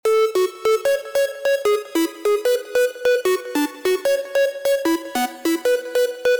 Lead (FxChain - Synth Lead 4)
Lead-FxChain-Synth-Lead-4.mp3